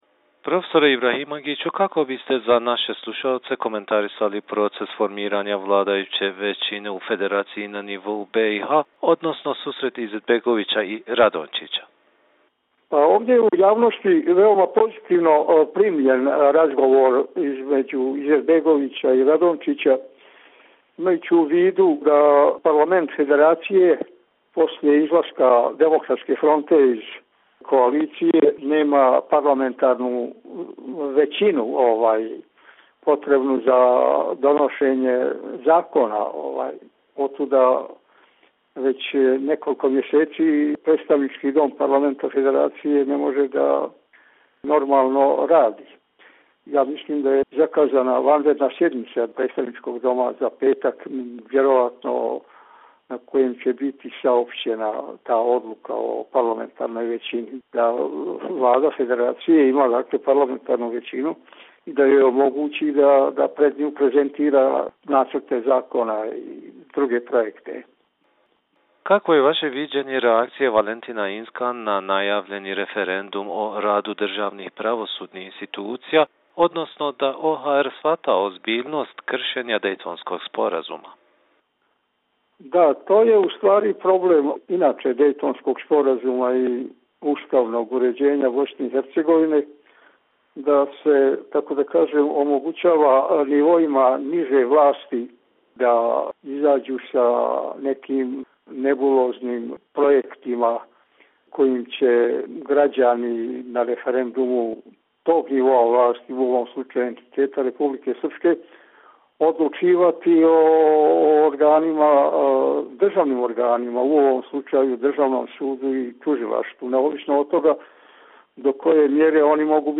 Tonske izjave